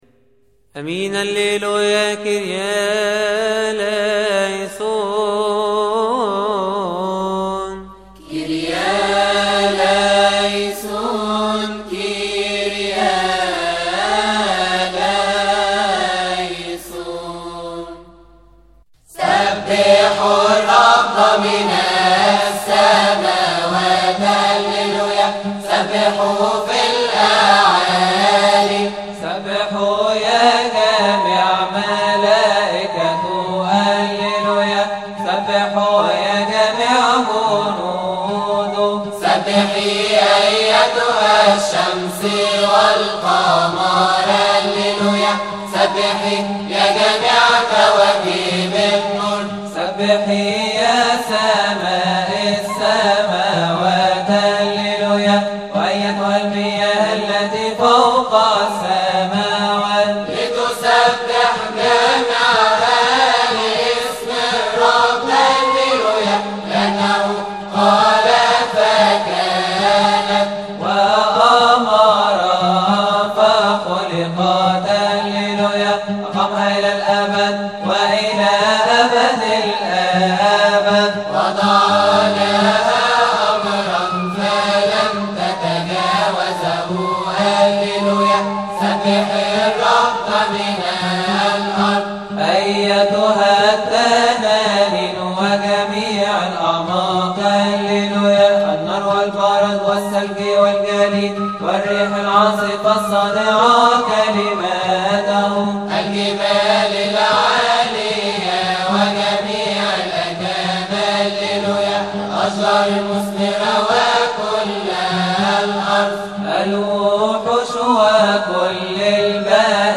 استماع وتحميل لحن الهوس الرابع عربى من مناسبة keahk